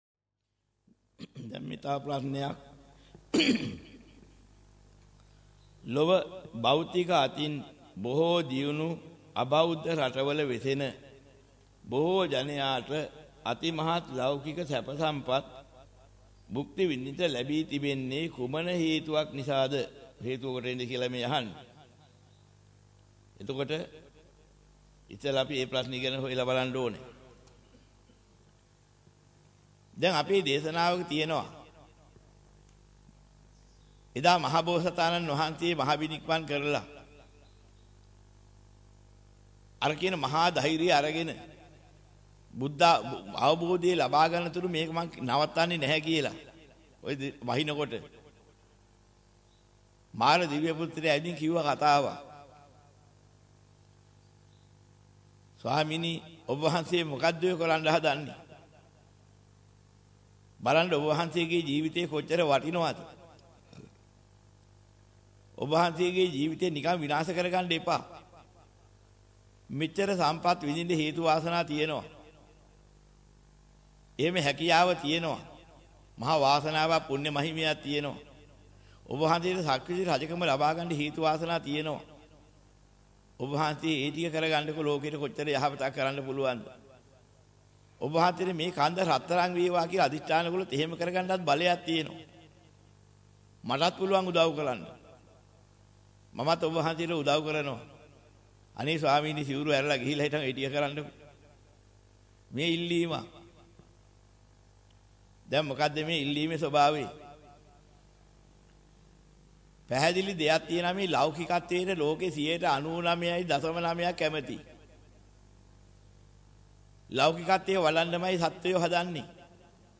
වෙනත් බ්‍රව්සරයක් භාවිතා කරන්නැයි යෝජනා කර සිටිමු 11:24 10 fast_rewind 10 fast_forward share බෙදාගන්න මෙම දේශනය පසුව සවන් දීමට අවැසි නම් මෙතැනින් බාගත කරන්න  (5 MB)